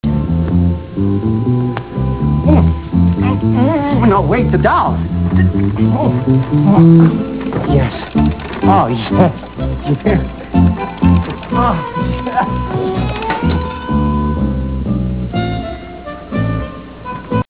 Classical/Instrumental
Comment: campy